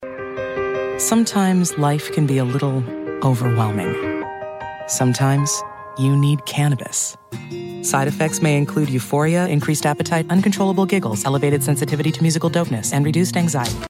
Medical Narration
She makes even complex subjects clear.
pharma-medical-terminology-parody-legal-comedian-human-trustworthy.mp3